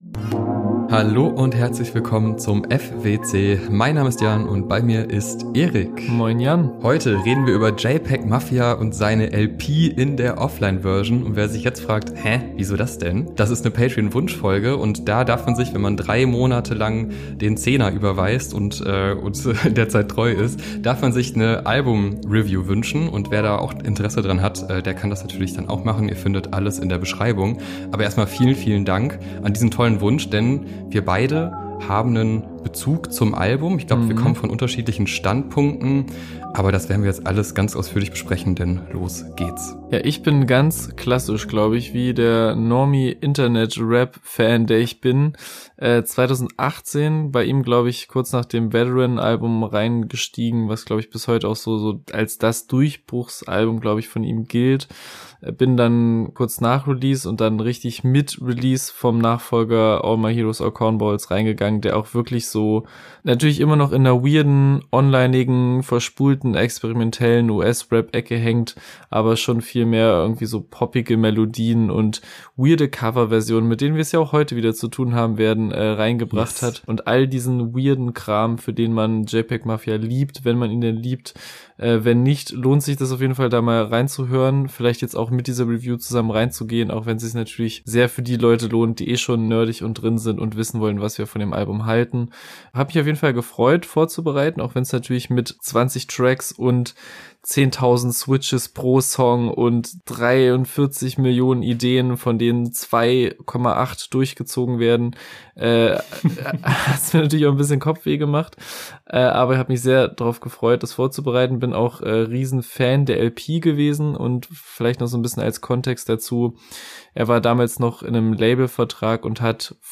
Viel Spaß mit unserer Review zur Offline-Version der »LP«!